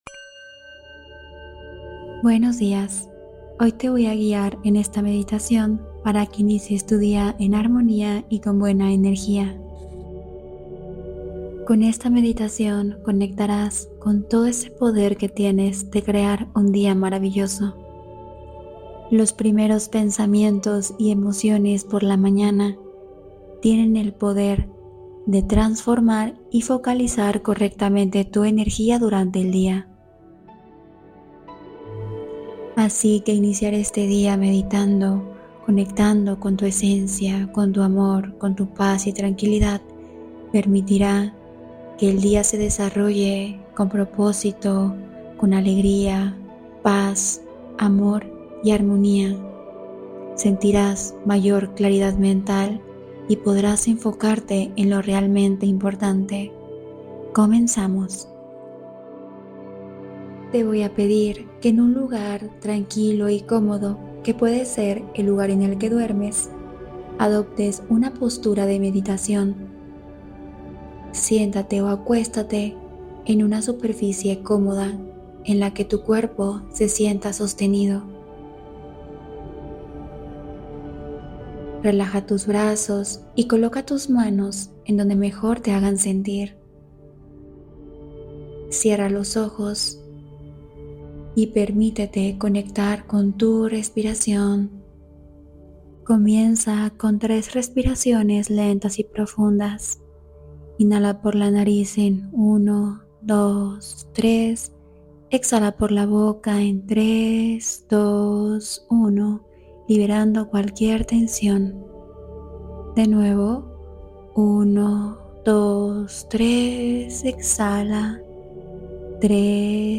Suelta la Tensión del Amanecer: Meditación para Iniciar el Día en Serenidad